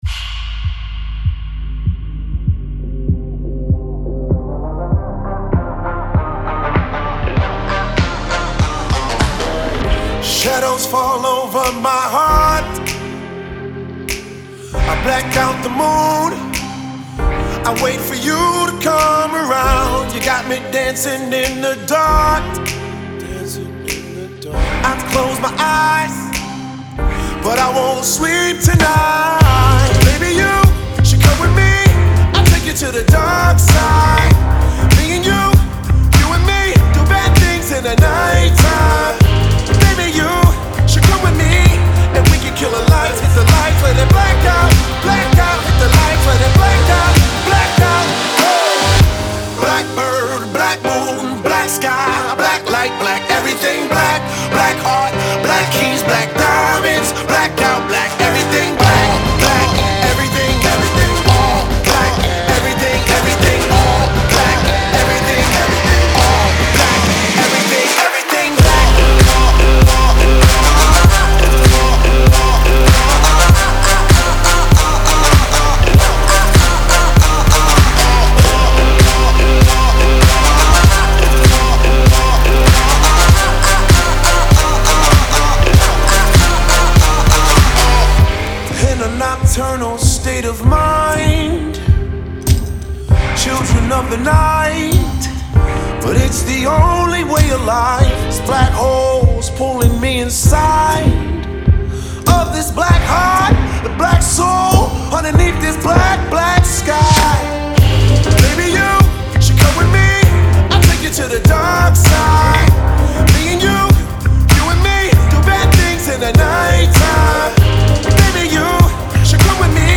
Электроника